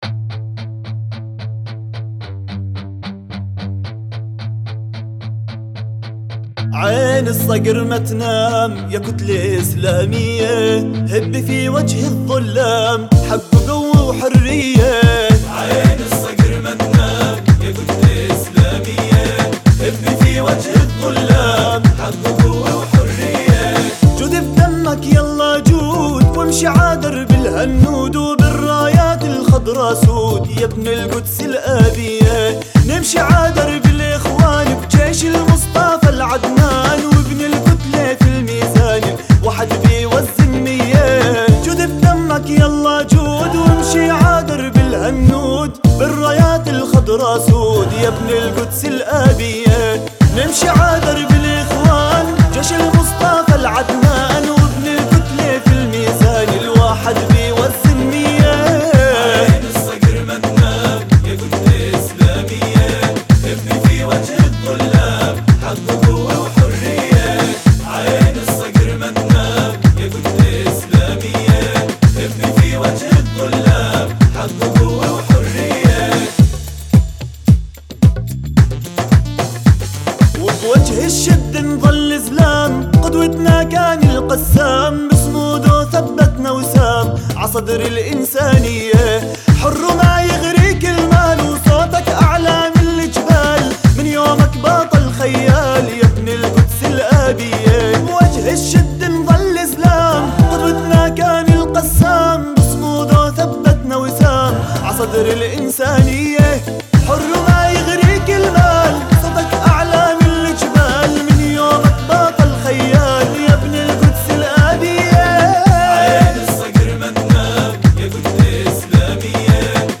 أناشيد فلسطينية... عين الصقر ما تنام يا كتلة إسلامية